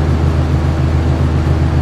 fan.ogg